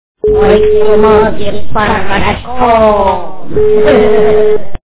При прослушивании Масяня - Мойте мозги порошком качество понижено и присутствуют гудки.